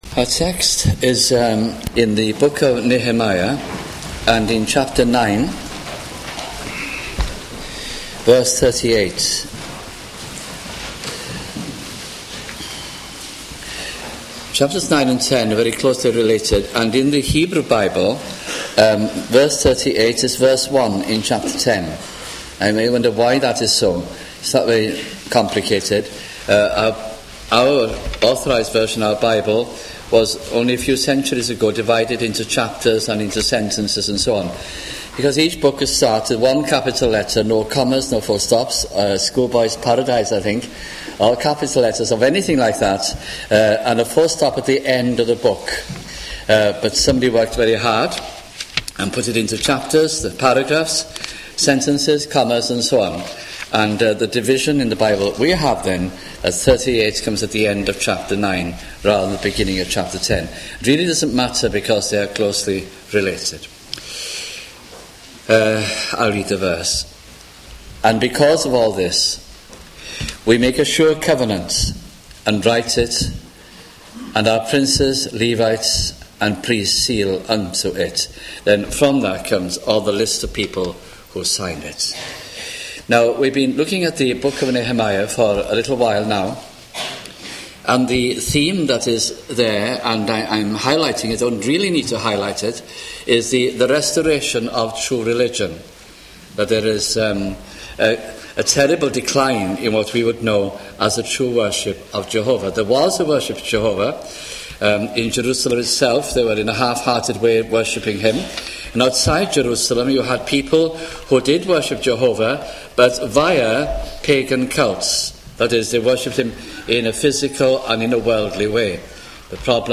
» Nehemiah 1996 » sunday morning messages